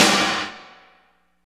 55.03 SNR.wav